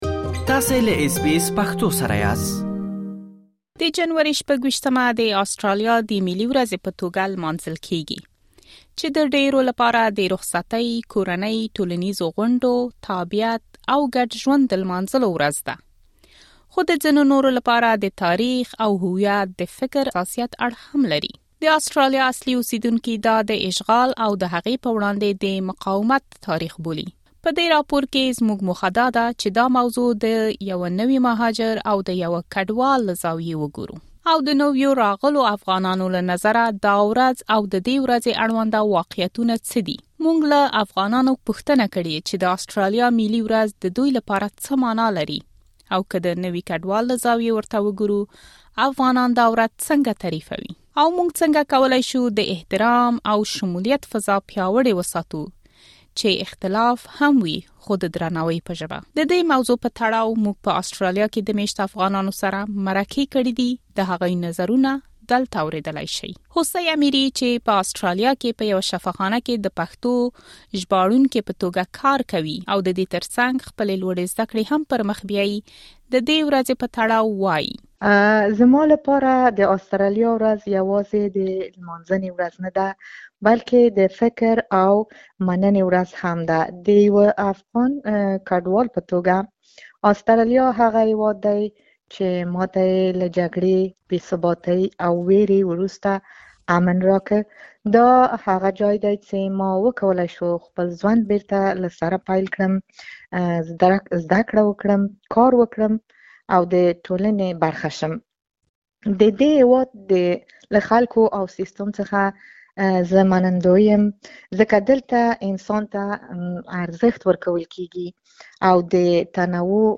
د اسټرالیا اصلي اوسېدونکي دا د یرغل او ماتم ورځ بولي. اس بي اس پښتو په همدې اړه د یو شمېر نویو راغلو افغانانو نظرونه را اخیستي.